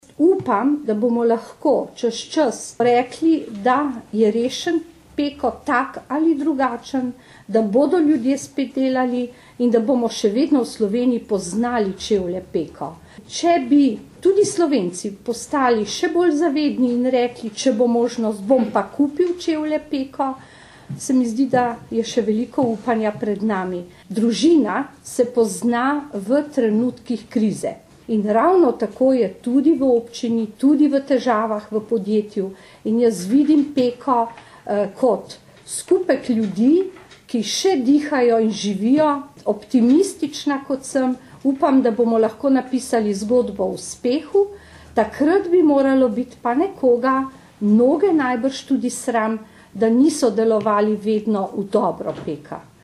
55977_izjavavaruhinjeclovekovihpravicvlastenussdorfer.mp3